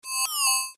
На этой странице собраны звуки уведомлений для электронной почты — короткие и четкие сигналы о новых письмах.
Звук нового письма в электронной почте